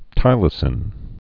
(tīlə-sĭn)